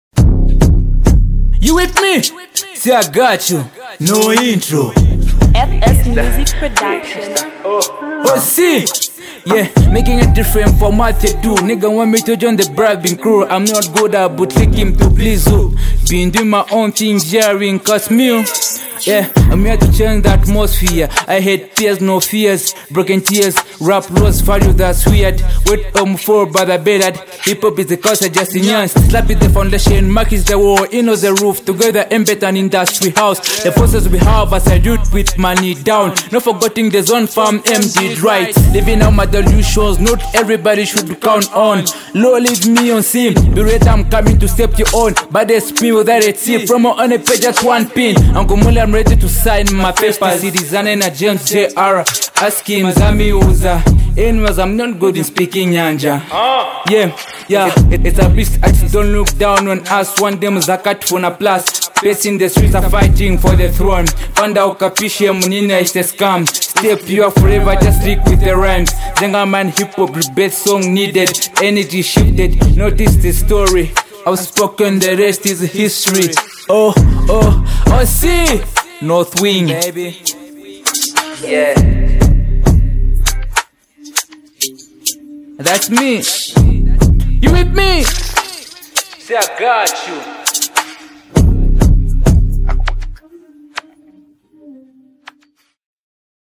sharp bars
energetic vibe